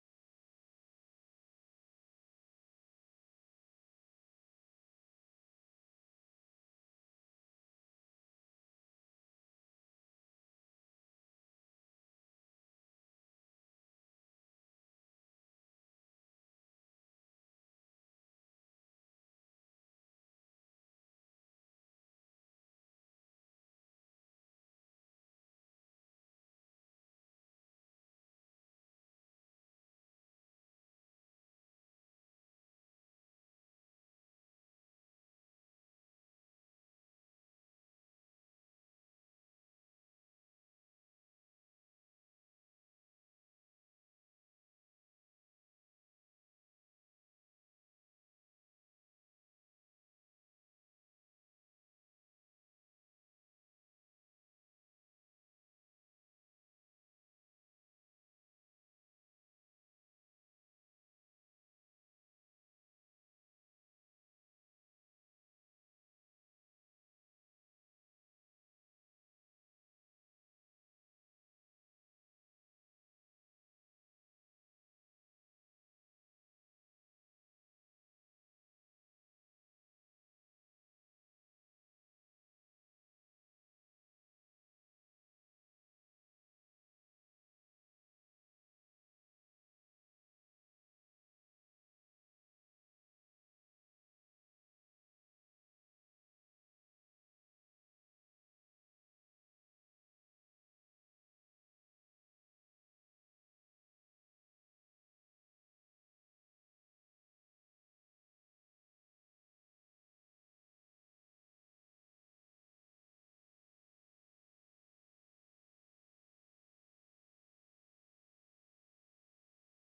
Praise Worship